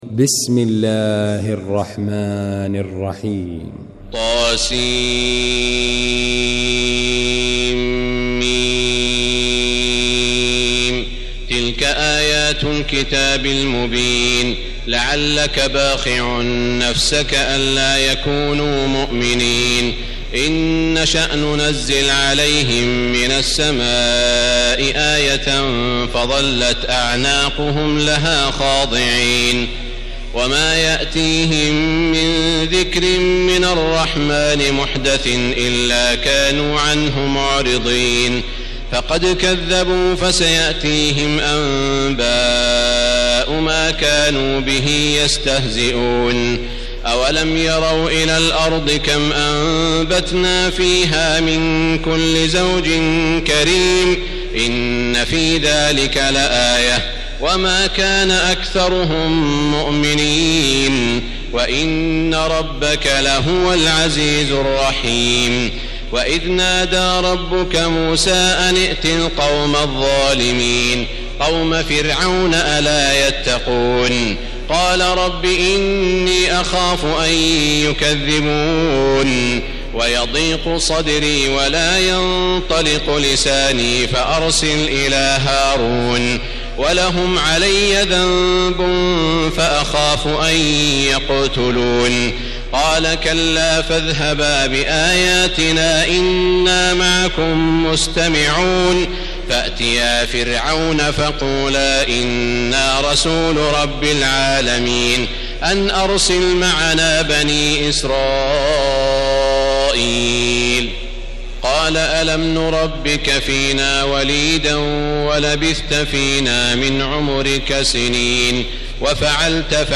المكان: المسجد الحرام الشيخ: سعود الشريم سعود الشريم معالي الشيخ أ.د. عبدالرحمن بن عبدالعزيز السديس الشعراء